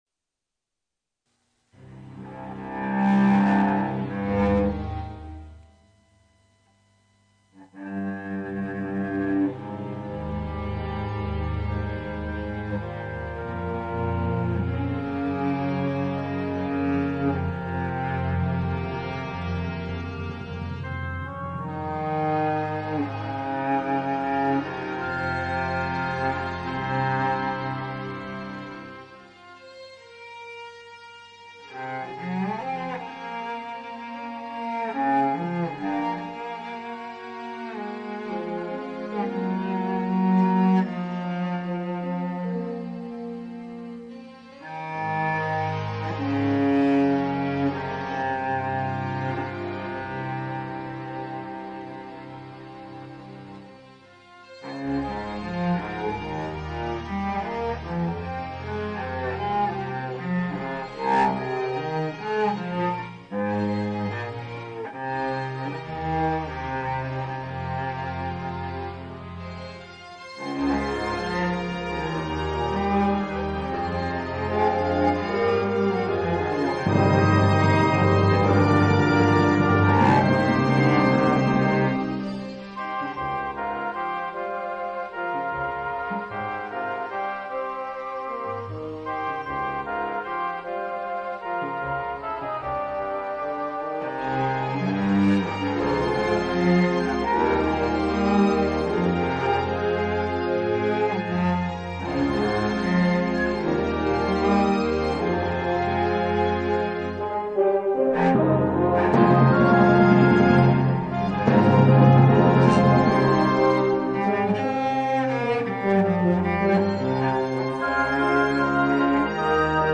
※音量注意！例によってｵｹmidiとは後から重ね録音してる関係で
Vc
このmidiはほぼ教室で演奏するｱﾝｻﾝﾌﾞﾙ譜と同じ旋律です。
微妙な音程の違いは…単純に私の技術的な問題です（笑）
このmidiの方がちょっと速度が速いんです。